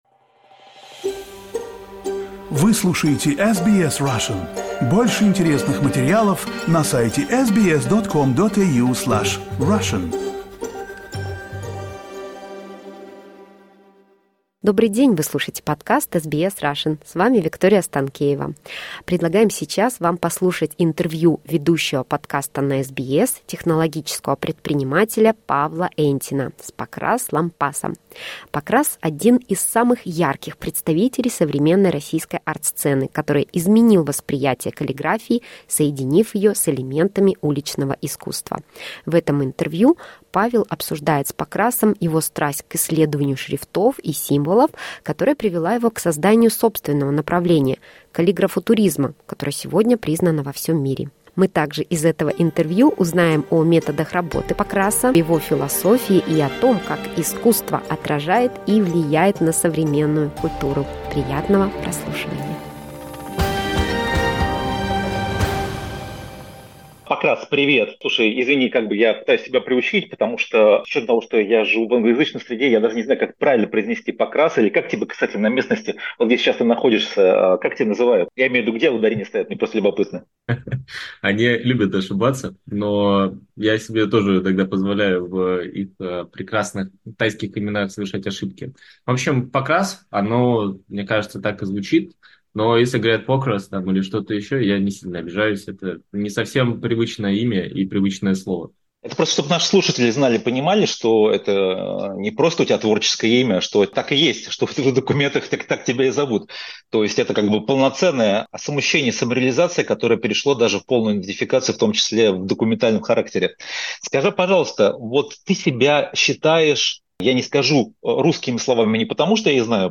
Artist's Journey: Interview with Pokras Lampas
Interview with Pokras Lampas, a Russian artist known for his unique style of calligrafuturism that combines traditional calligraphy and street art elements.